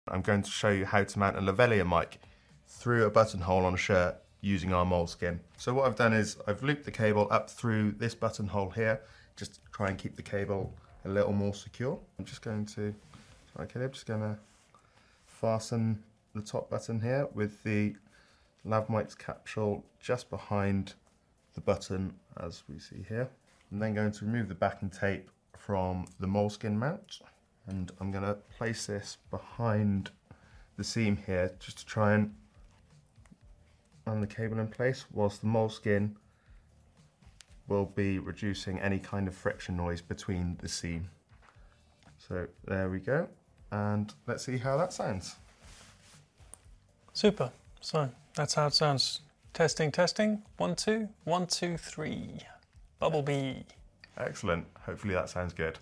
A super simple button hole rig by using The Invisible Lav Covers - Moleskin to secure it just behind the button hole of a shirt